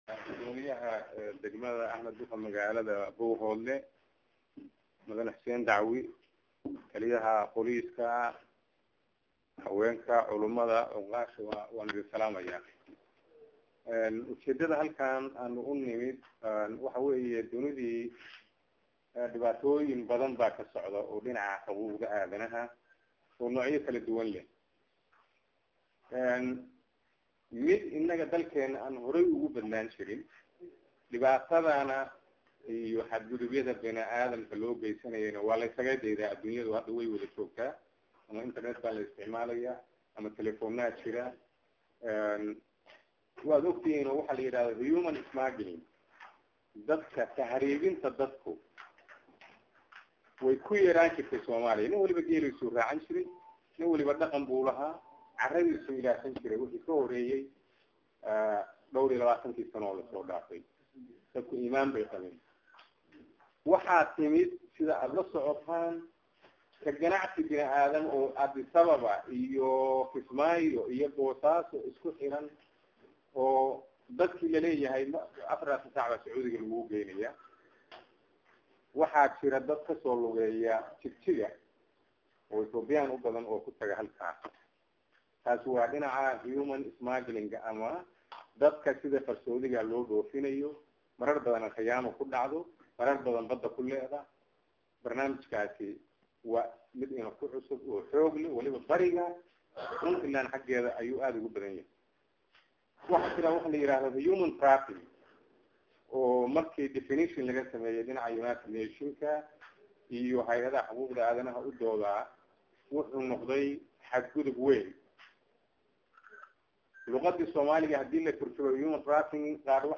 Waxaa Maanta Magaalada Buuhoodle lagu Qaybtay kulan balaadhan oo looga hadlay xaquuqda aadanaha iyo taciyada loo gaysto dadka ka soo Jeeda koonfurta somalia iyo Waliba dadka la adoonsado Waxaana kulan kaasi soo Qaban Qaabiyey NGO UNGAM oo Saldhigeesu yahay magaalada Boosaaso ee Xarunta gobolka bari.